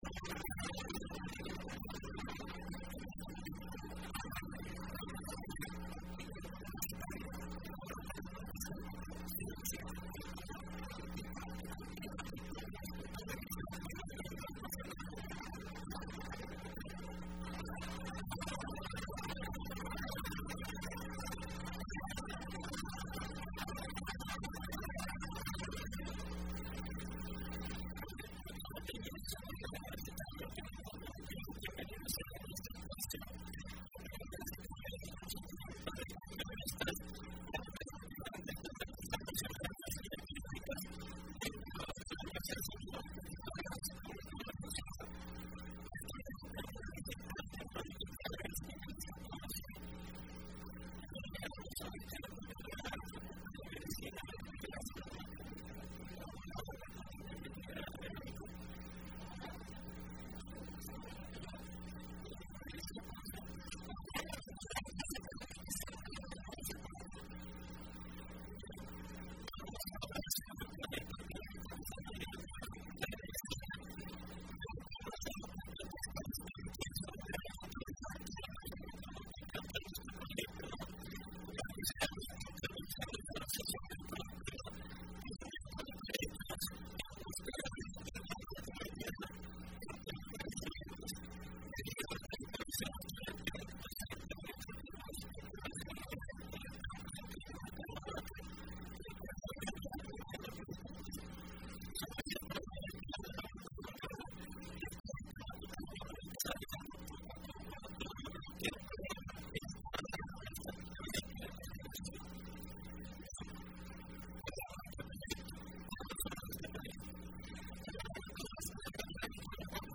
Entrevista Opinión Universitaria